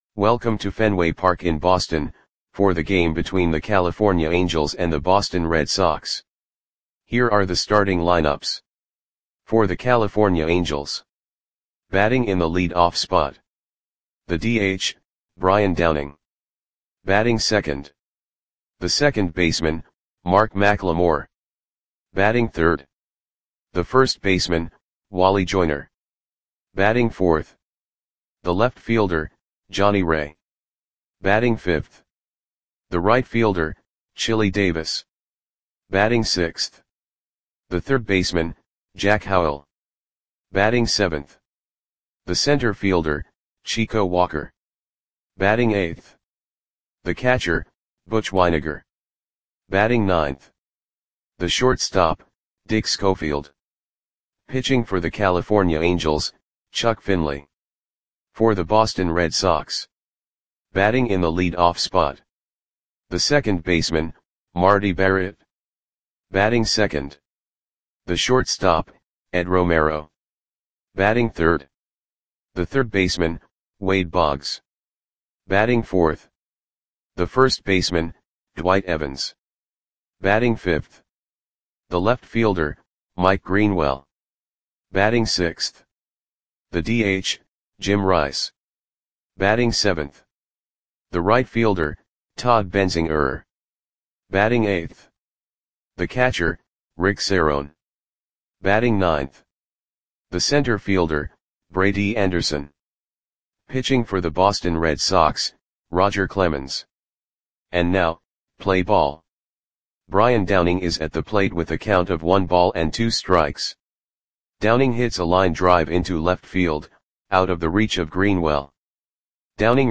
Lineups for the Boston Red Sox versus California Angels baseball game on May 20, 1988 at Fenway Park (Boston, MA).
Click the button below to listen to the audio play-by-play.